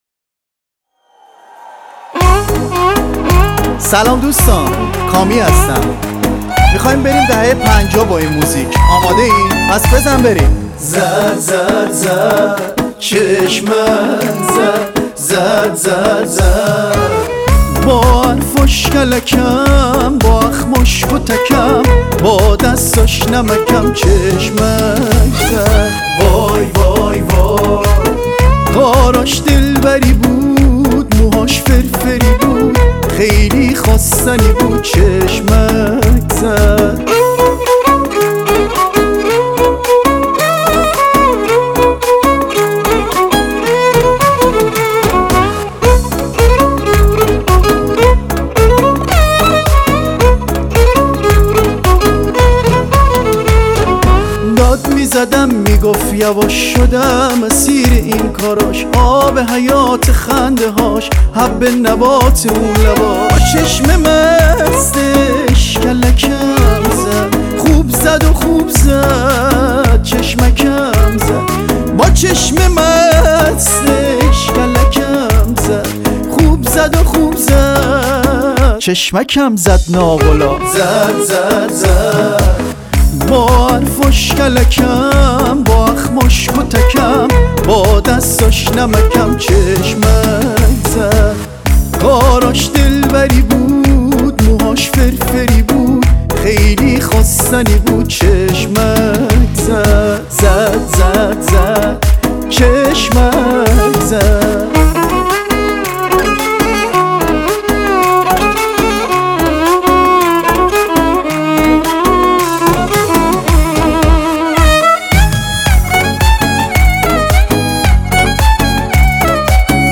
فارسی ریتمیک
آهنگ فارسی ریتمیک شاد